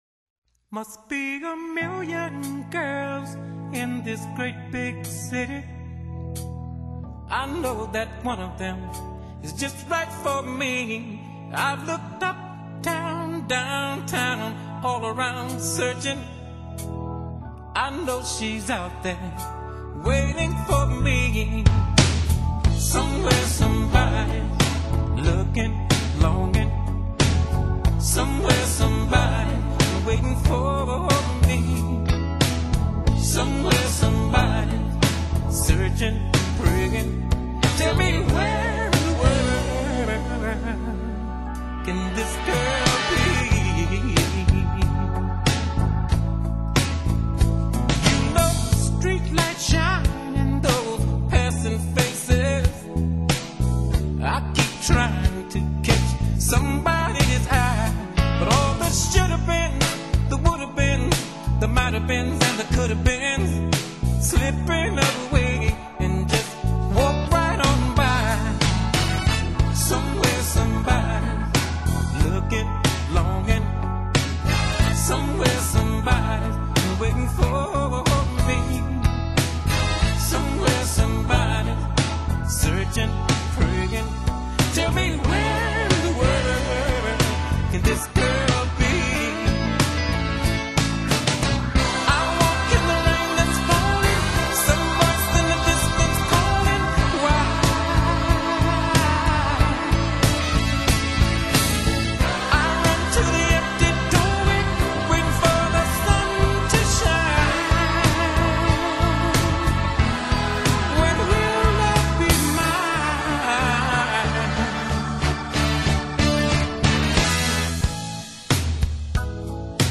高歌时那把浑然天成的嗓子，听似漫不经心，却令人神往。
全为一流抒情歌曲。录音勿须怀疑，暖暖的音色之中，乐器结像定位清晰，